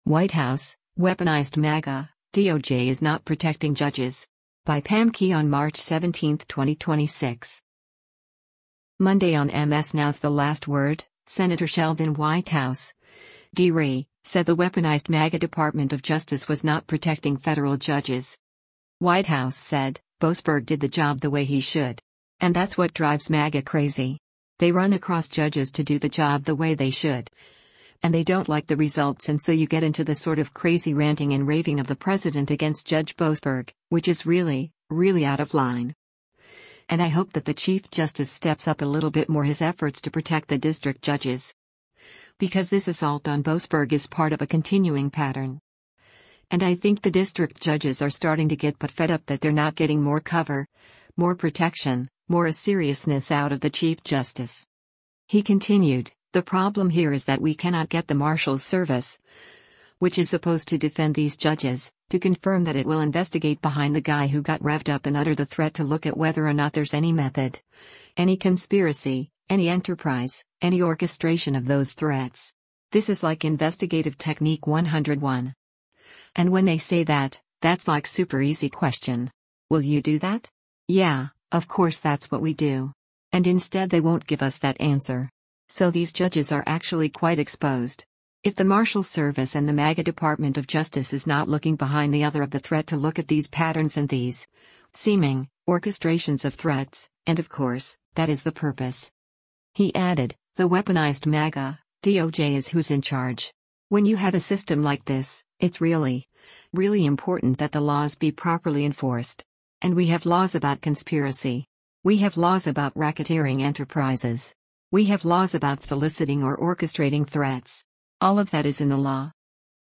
Monday on MS NOW’s “The Last Word,” Sen. Sheldon Whitehouse (D-RI) said the “weaponized MAGA” Department of Justice was not protecting federal judges.